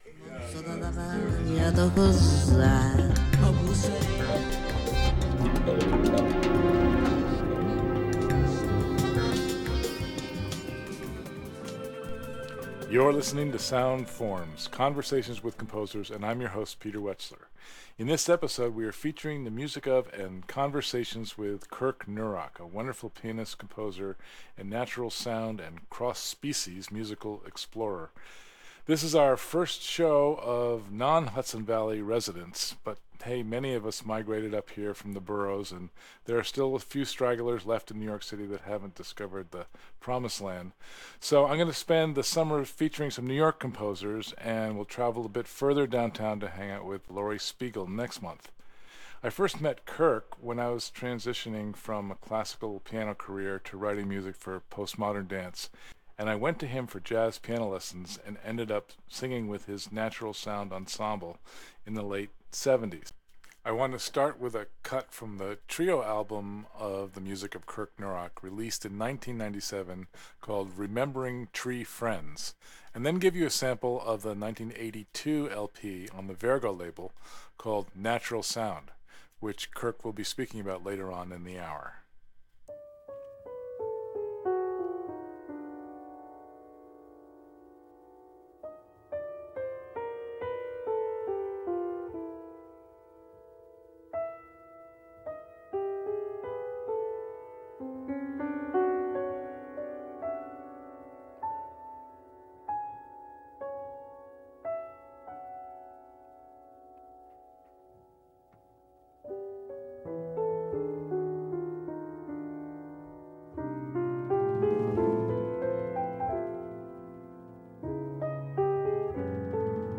Sound Forms: Conversations with Composers